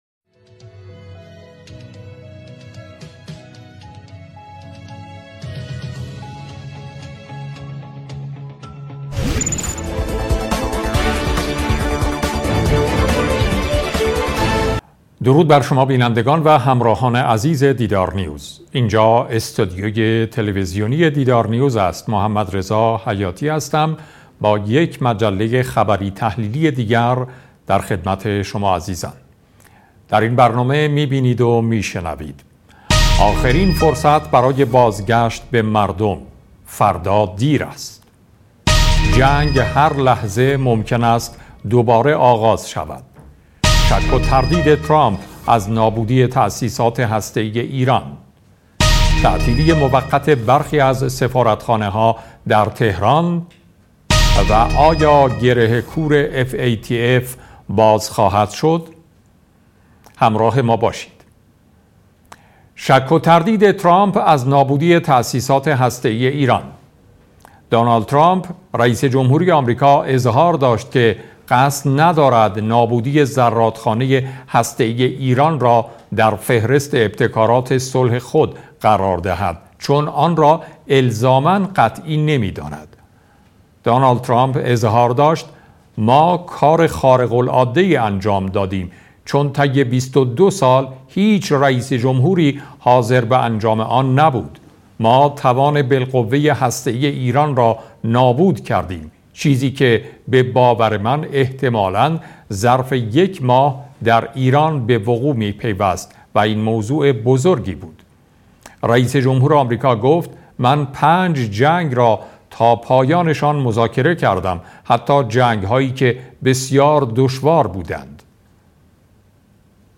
صدای مجله خبری 27 مرداد